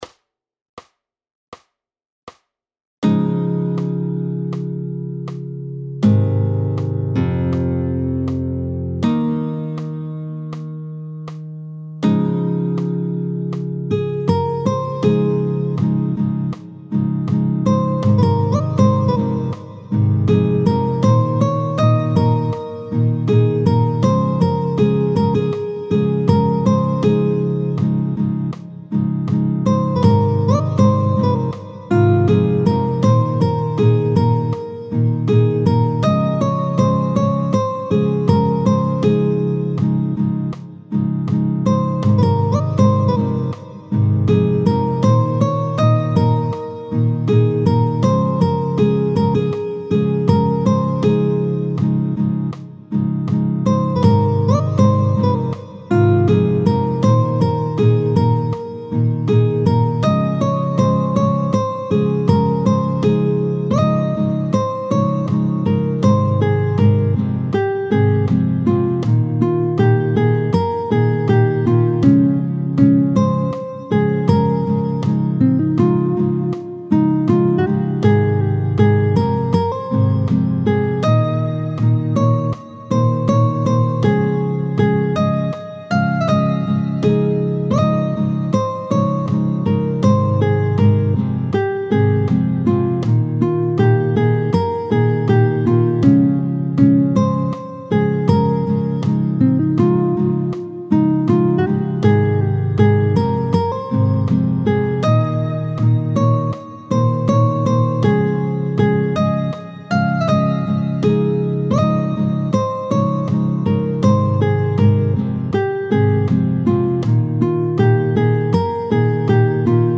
tempo 80